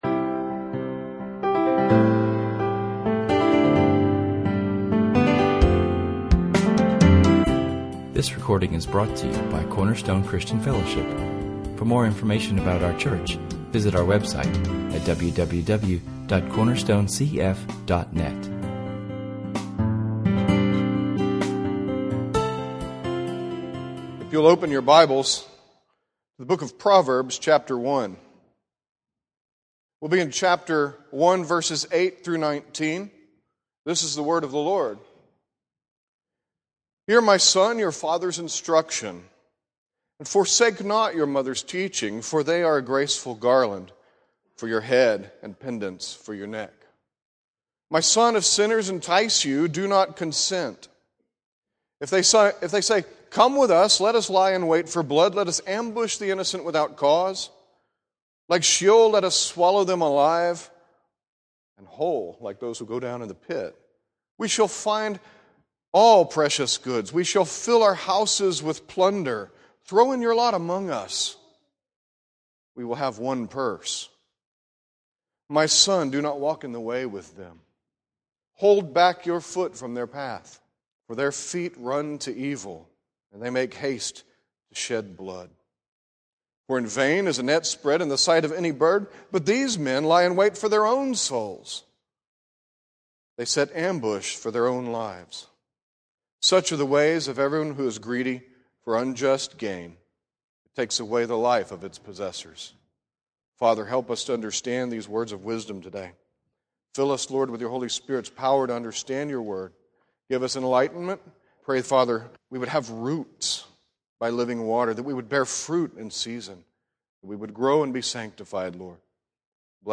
This is a good sermon for parents and older children to listen to together, especially if the foolish ways of the world have been successful in tempting our Christian sons and daughters.